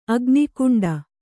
♪ agnikuṇḍa